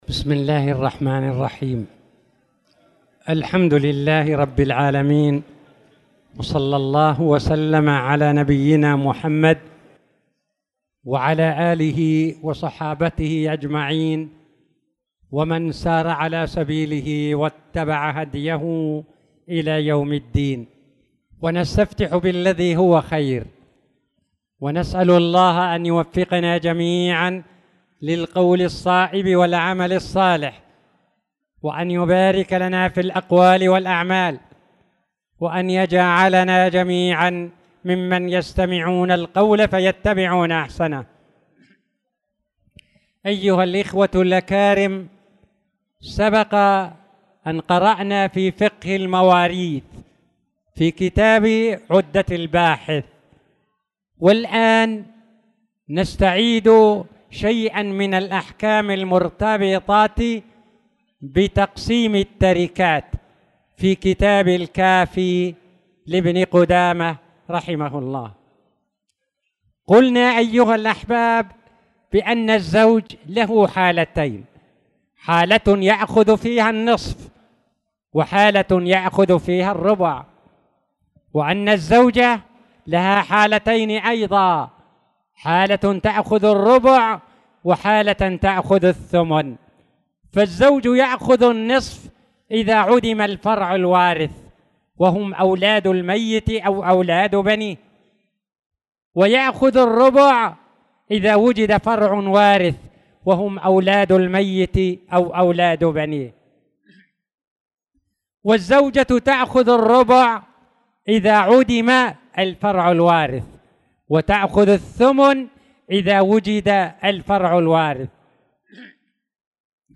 تاريخ النشر ٧ شوال ١٤٣٧ هـ المكان: المسجد الحرام الشيخ